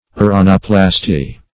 Search Result for " uranoplasty" : Wordnet 3.0 NOUN (1) 1. surgical correction of a defect of the palate ; The Collaborative International Dictionary of English v.0.48: Uranoplasty \U"ra*no*plas`ty\, n. [See Uraniscoplasty .]